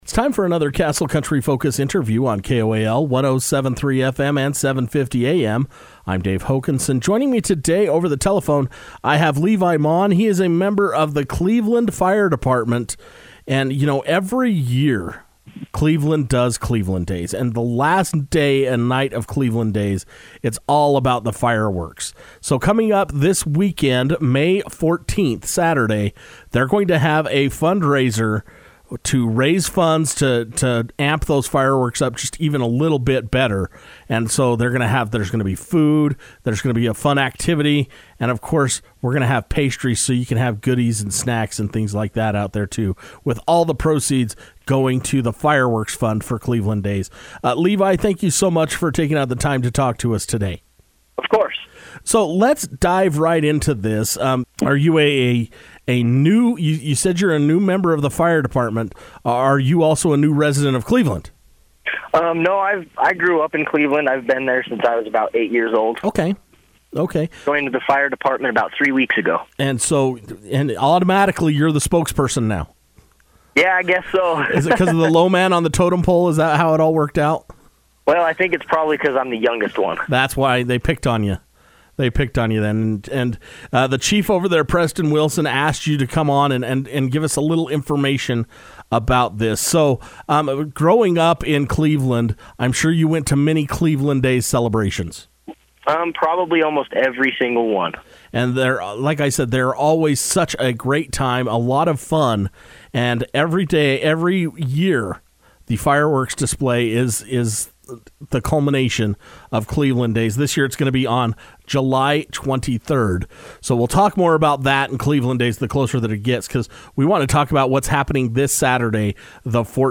The Cleveland Fire Department is hosting a fundraising event to help raise funds for the annual fireworks show that takes place in July. Castle Country Radio was able to speak over the telephone with Fireman